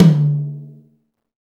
TOM XTOMHI0I.wav